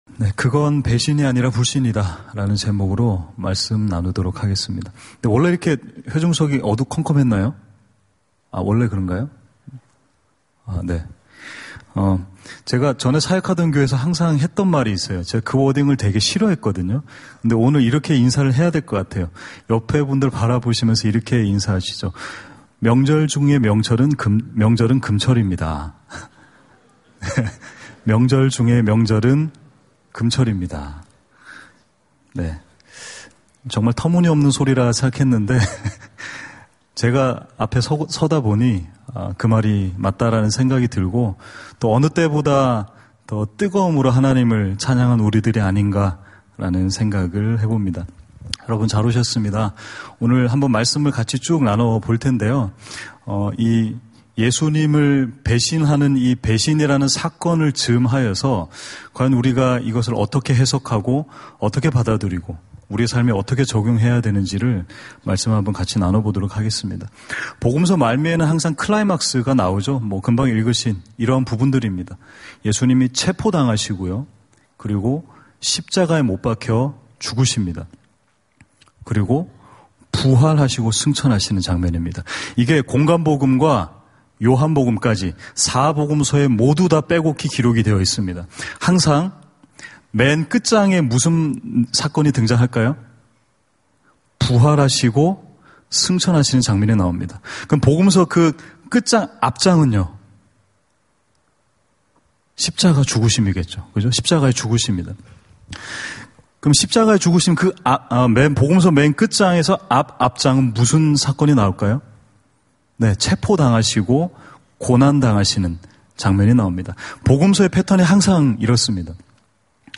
금요기도회